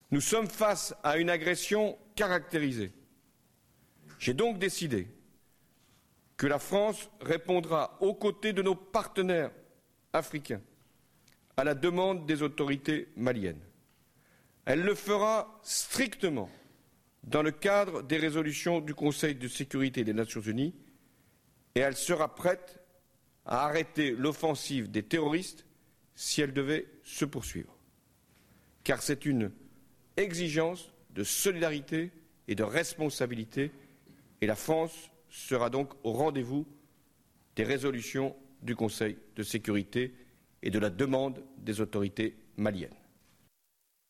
Allocution de François Hollande sur le Mali